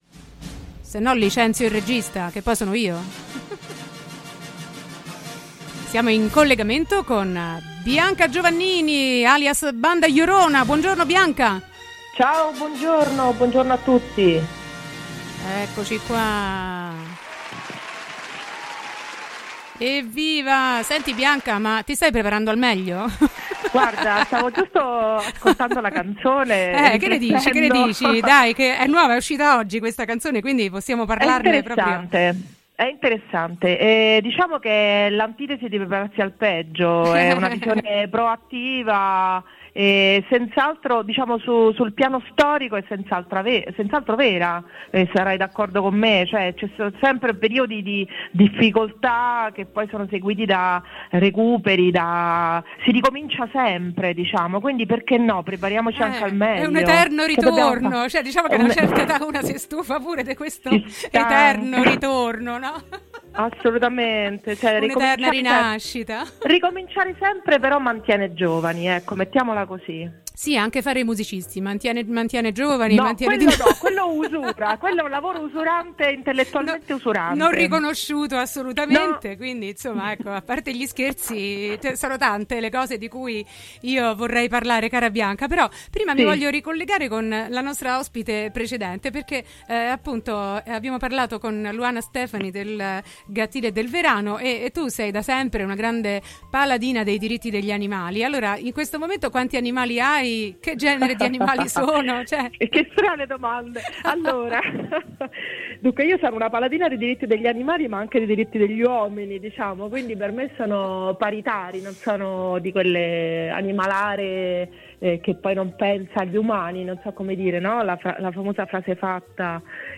Intervista a BandaJorona, 20.11.2020